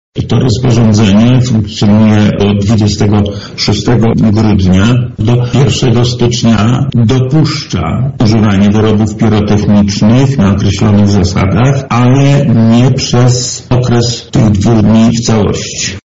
• mówi Lech Sprawka, Wojewoda Lubelski